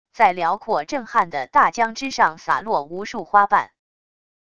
在辽阔震撼的大江之上洒落无数花瓣wav音频